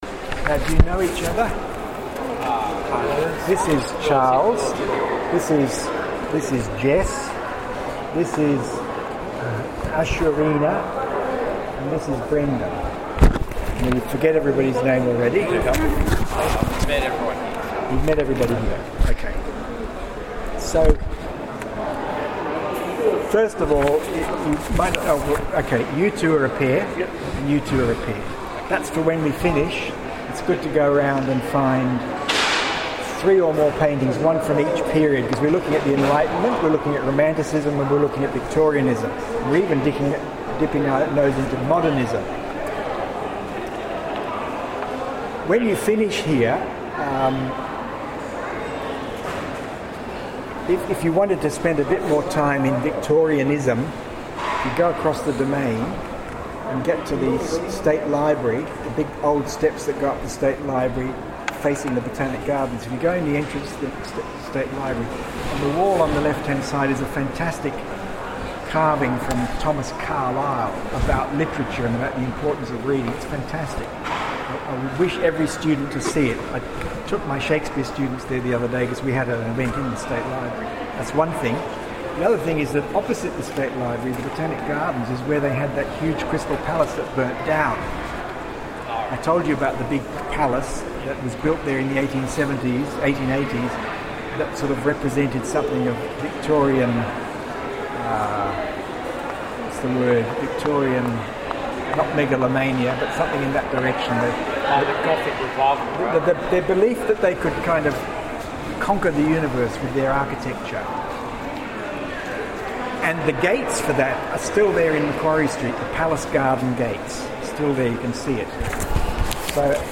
art-gallery-floor-talk-2017.mp3